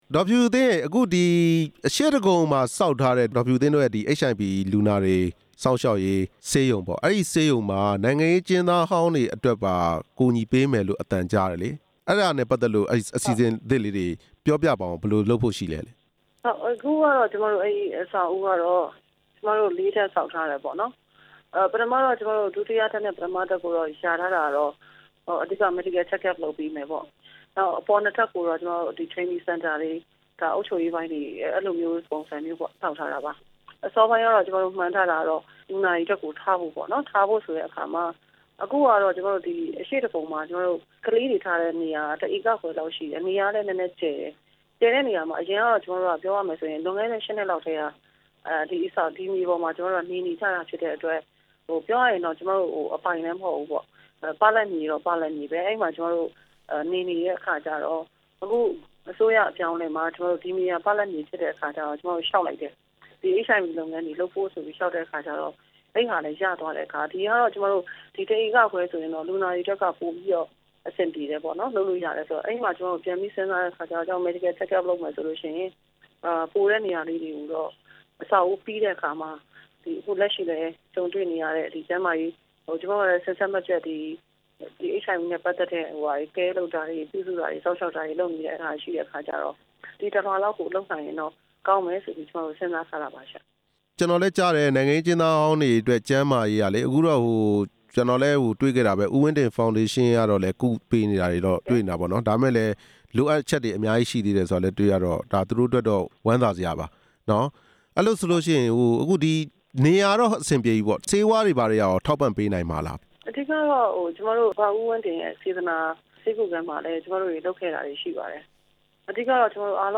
နိုင်ငံရေး အကျဉ်းသားဟောင်းတွေအတွက် ကျန်းမာရေးစောင့်ရှောက်မှု မဖြူဖြူသင်းနဲ့ မေးမြန်းချက်